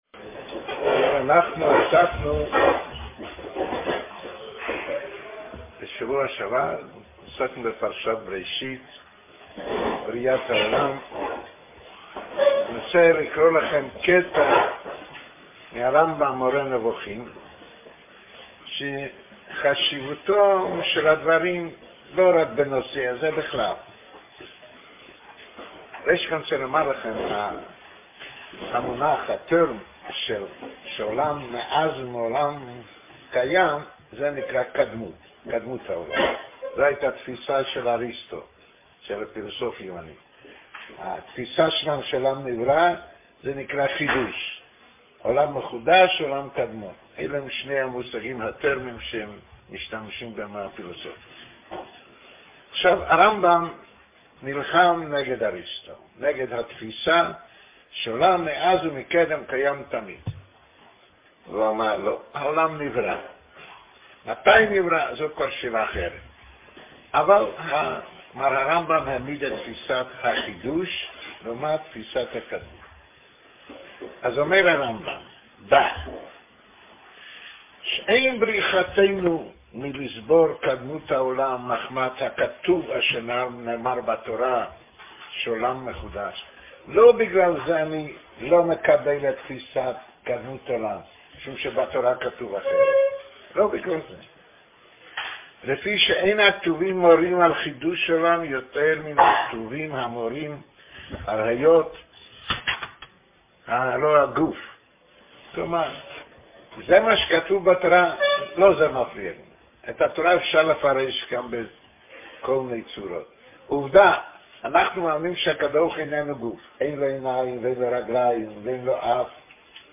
מתוך שיחה לתלמידי שנה א' בני חול, משנת 2003.